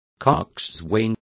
Complete with pronunciation of the translation of coxswains.